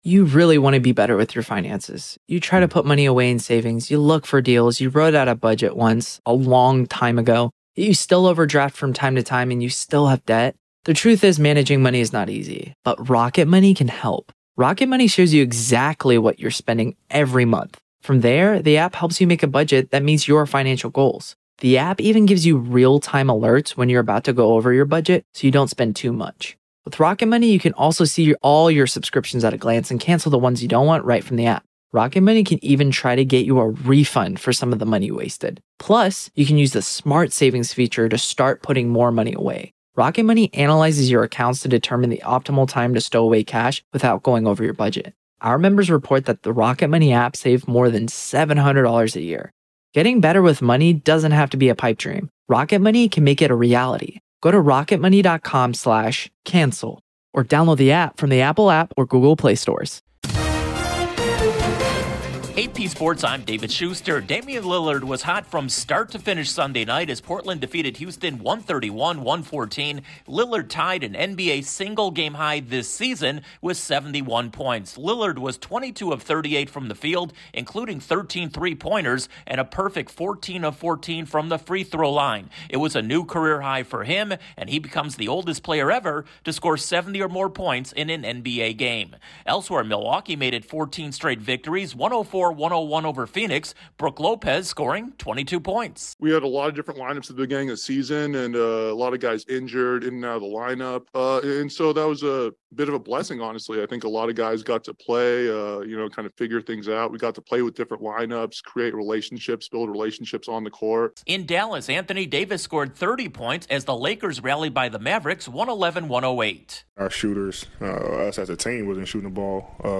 The latest in sports